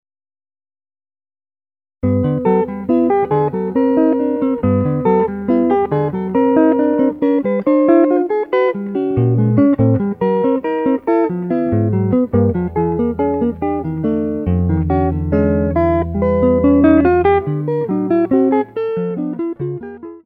solo electric guitar